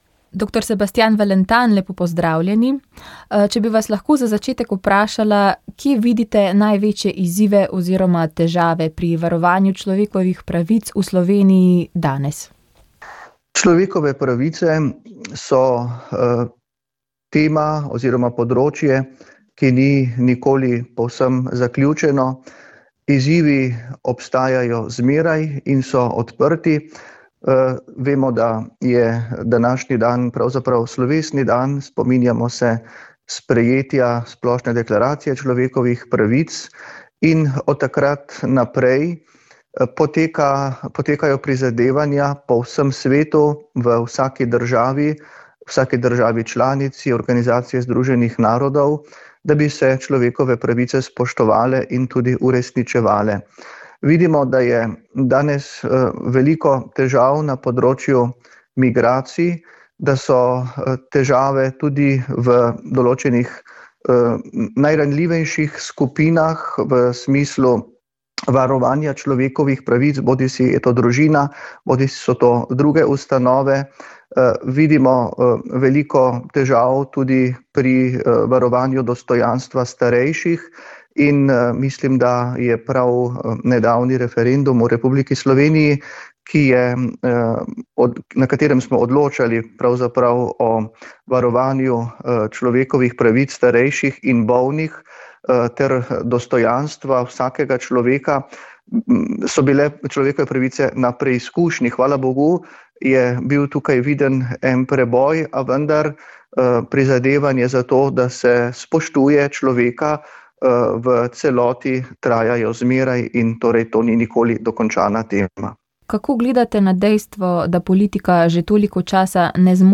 Pridiga nadškofa Stanislava Zoreta ob biseromašnem jubileju kardinala Franca Rodeta
V slovenskem narodnem svetišču Marije Pomagaj, je bilo kljub epidemiji novega koronavirusa ob prazniku Marijinega Vnebovzetja zelo slovesno. Že od jutra so prihajali romarji, ki so dopoldne napolnili trg pred baziliko in park miru pri slovesni sveti maši.